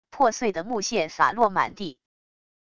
破碎的木屑洒落满地wav音频